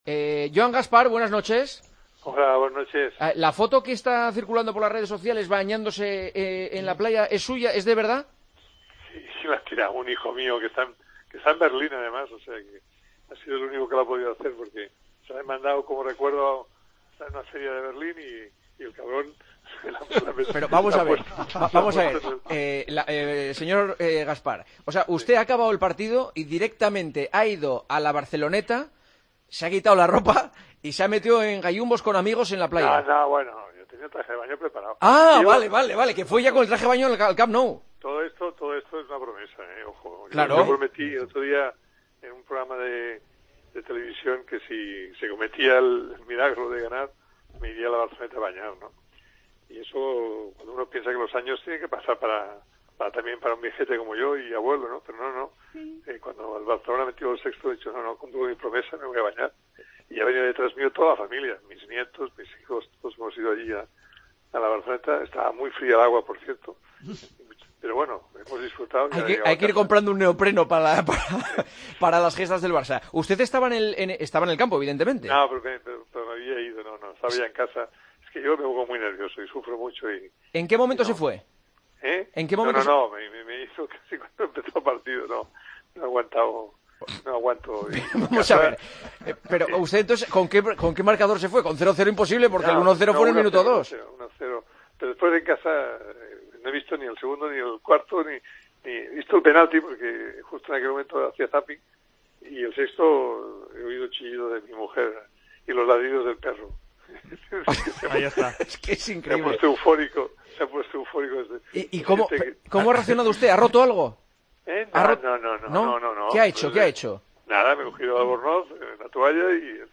El ex presidente del Barcelona atendió la llamada de El Partidazo de COPE tras haber cumplido su promesa de bañarse en La Barceloneta tras la clasificación del Barcelona: "Ya tenía el traje de baño preparado, el agua estaba muy fría. No aguanté todo el partido, me he enterado del sexto gol por los gritos de mi mujer y los ladridos del perro".